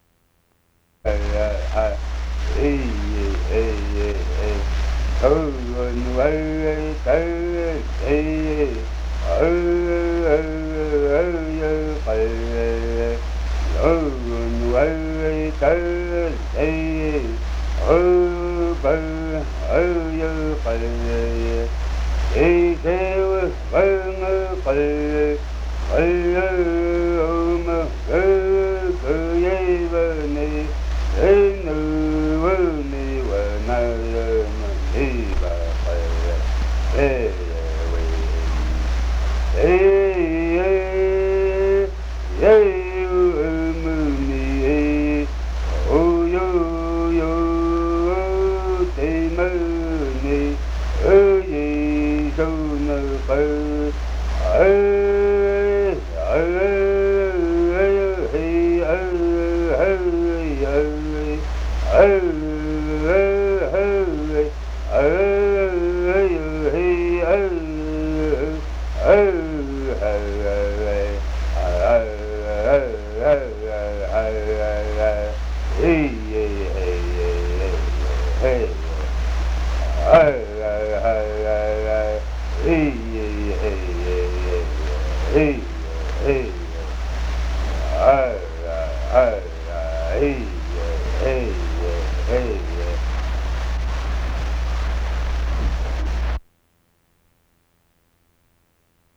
Music (performing arts genre)